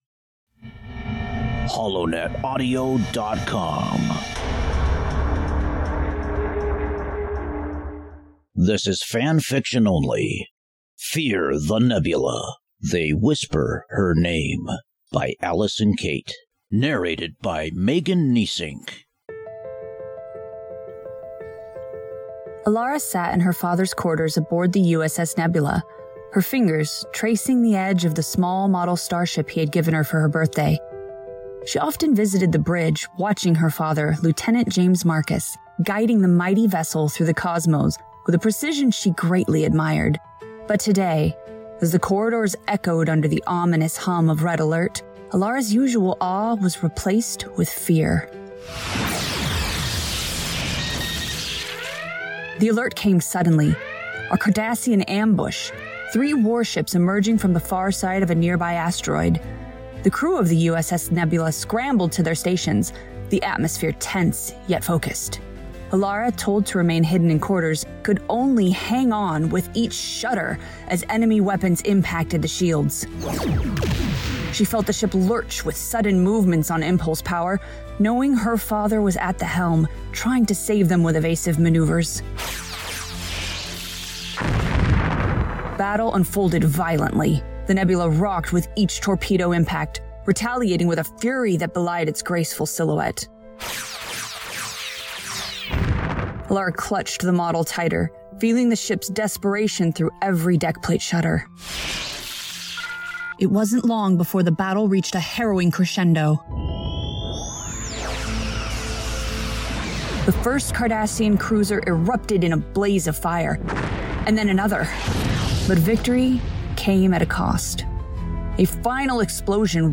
This story explains why the Cardassians fear the Nebula class of starships. Also includes a theme song after the story.
Audio Books/Drama Author(s